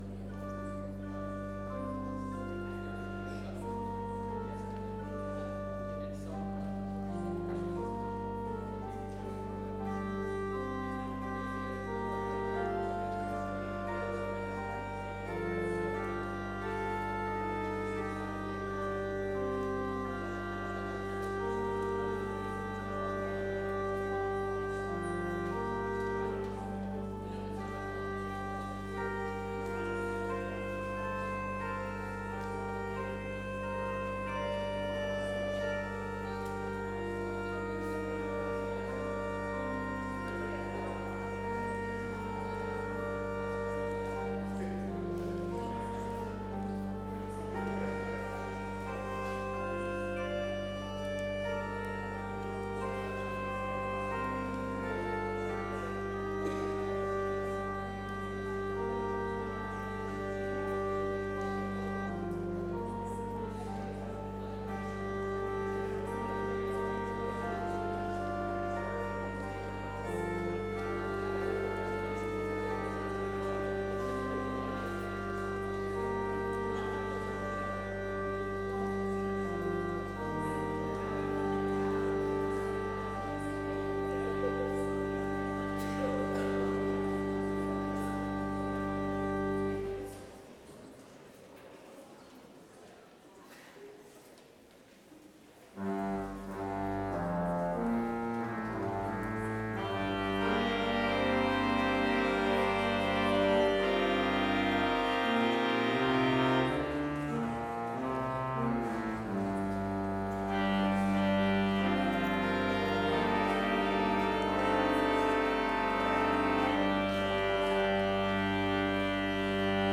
Complete service audio for Chapel - Monday, December 8, 2025